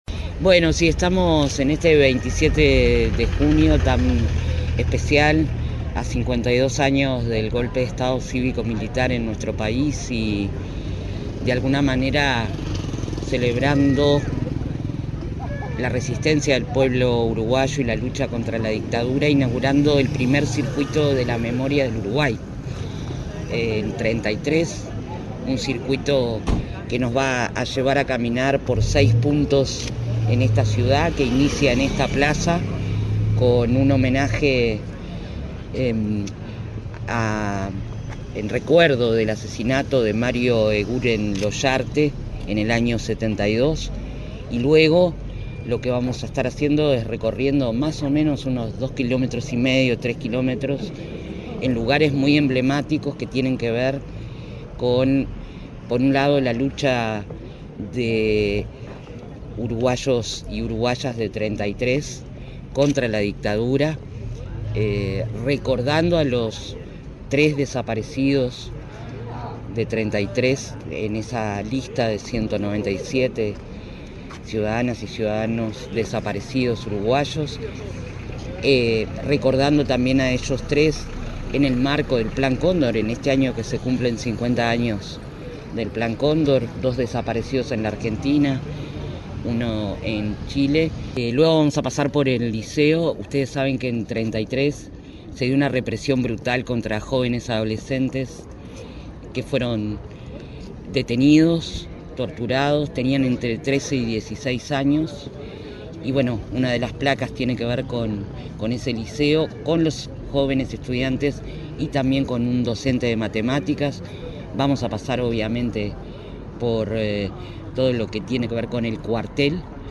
Declaraciones de la secretaria de Derechos Humanos para el Pasado Reciente, Alejandra Casablanca 27/06/2025 Compartir Facebook X Copiar enlace WhatsApp LinkedIn La titular de la Secretaría de Derechos Humanos para el Pasado Reciente, Alejandra Casablanca, dialogó con la prensa, en el marco de la inauguración del Circuito de Memoria en el departamento de Treinta y Tres.